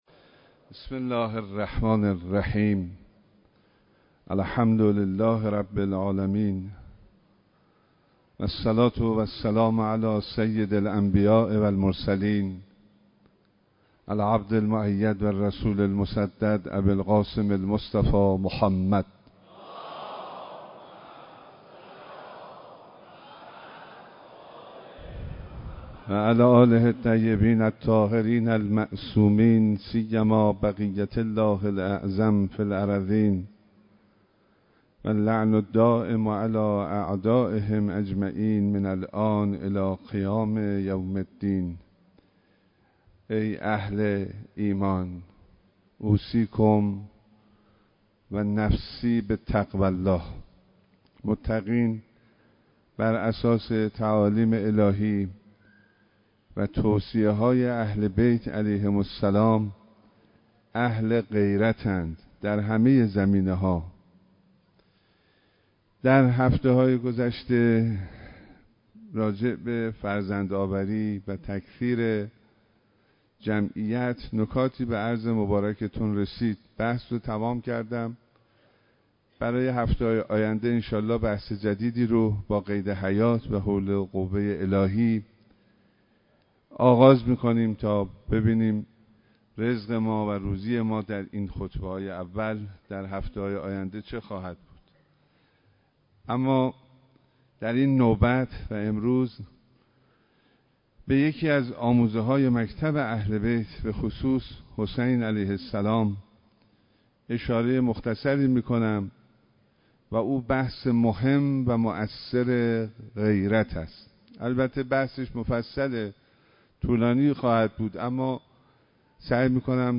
ایراد خطبه‌های نماز جمعه شهرستان کرج به امامت آیت‌الله حسینی همدانی نماینده ولی‌فقیه در استان البرز و امام‌جمعه کرج
صوت خطبه‌های نماز جمعه ششم مردادماه شهرستان کرج
به گزارش روابط عمومی دفتر نماینده ولی‌فقیه در استان البرز و امام‌جمعه کرج، نماز جمعه ششم مردادماه هزار و چهارصد و دو شهرستان کرج به امامت آیت‌الله حسینی همدانی در مصلای بزرگ امام خمینی (ره) برگزار شد.